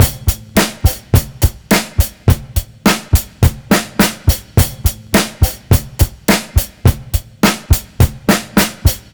RI BEAT 1 -R.wav